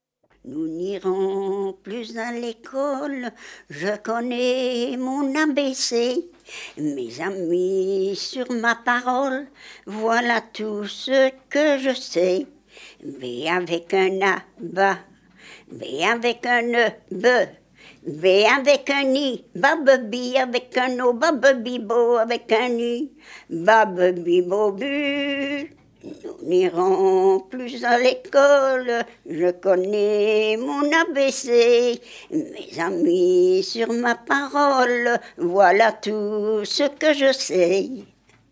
Genre : chant
Type : chanson d'enfants
Lieu d'enregistrement : Vierves-sur-Viroin
Support : bande magnétique